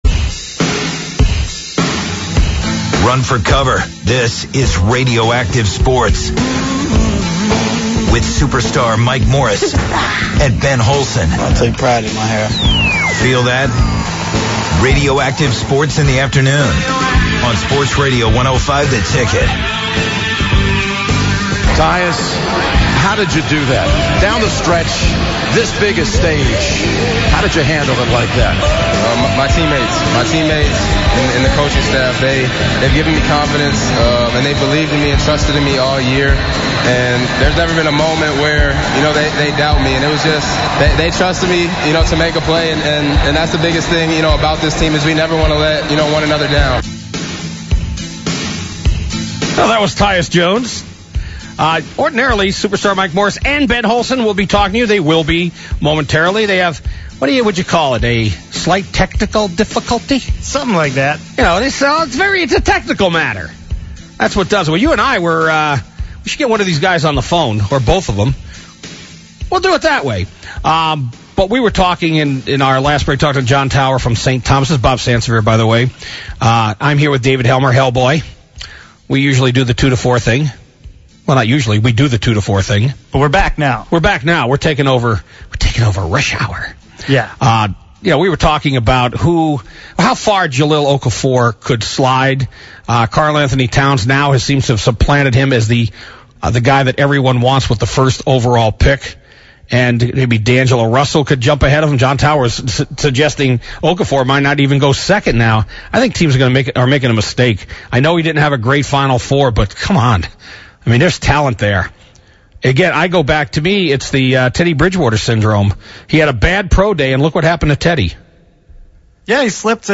live from Hubert's in Minneapolis.